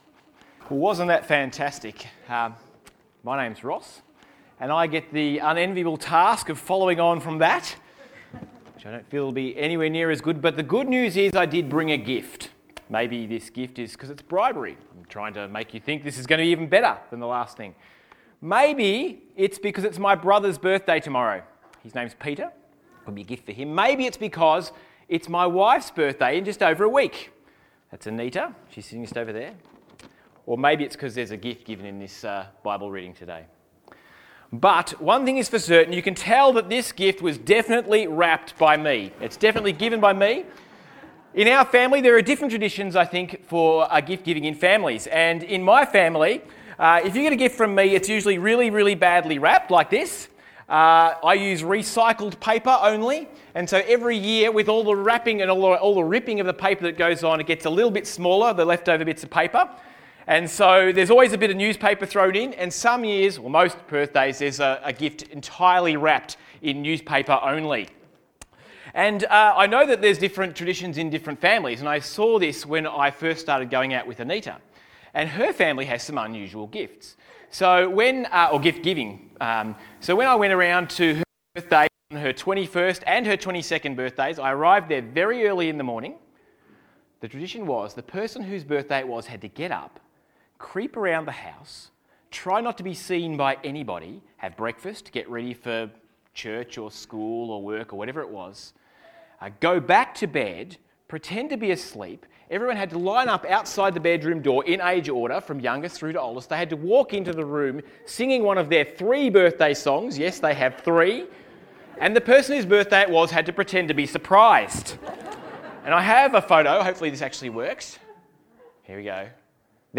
Pentecost All Age Service 2015